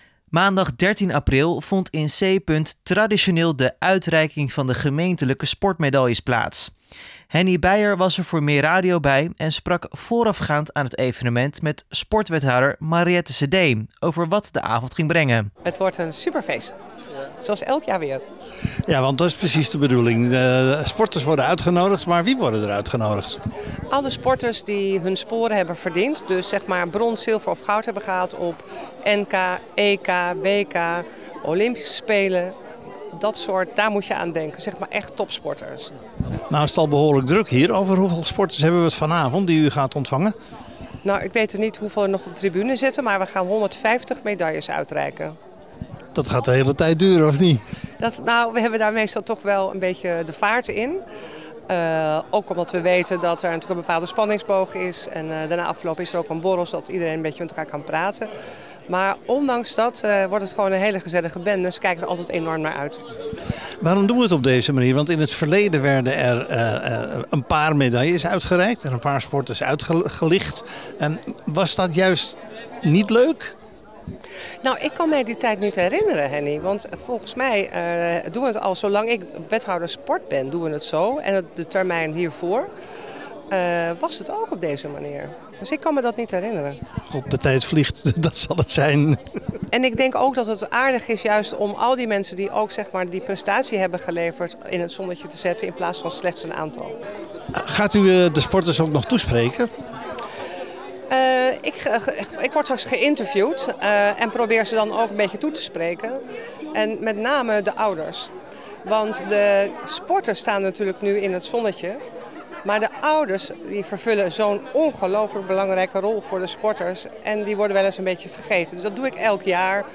audioreportage
Hij sprak vooraf met sportwethouder Mariëtte Sedee over wat er op het programma stond en legde vervolgens de sfeer vast tijdens de uitreiking.